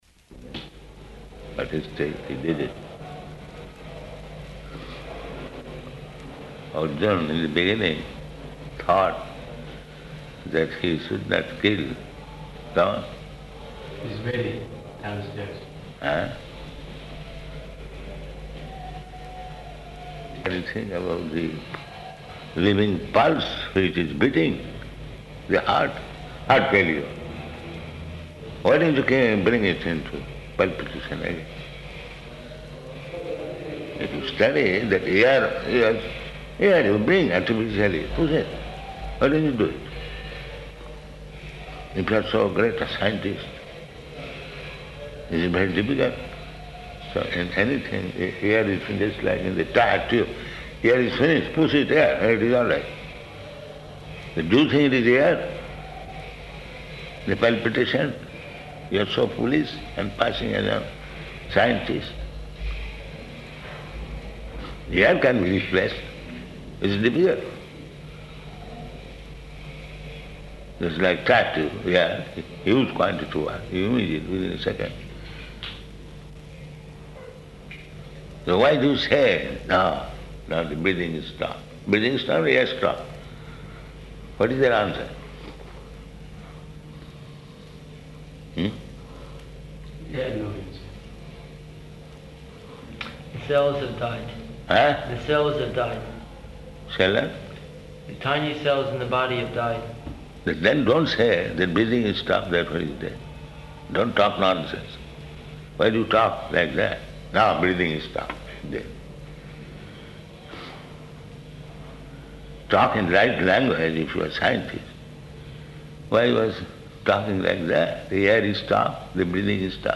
Room Conversation & TV Interview
-- Type: Conversation Dated: April 20th 1976 Location: Melbourne Audio file